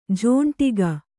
♪ jhōṇṭiga